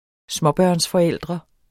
Udtale [ ˈsmʌbɶɐ̯ns- ]